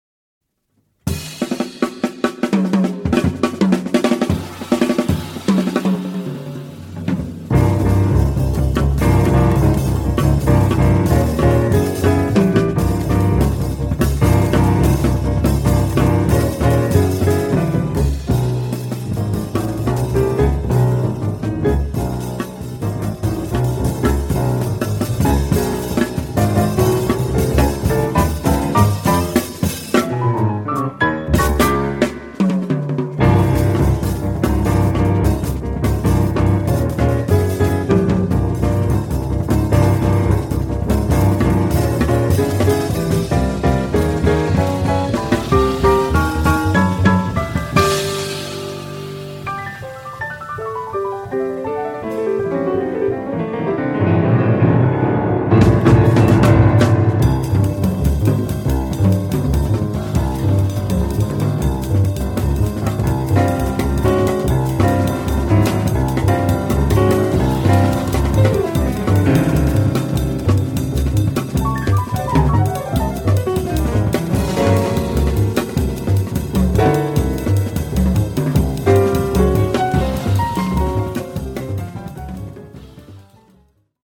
意味はわかりませんが、変拍子をぶっとばせということでしょうか？